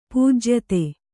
pūjyate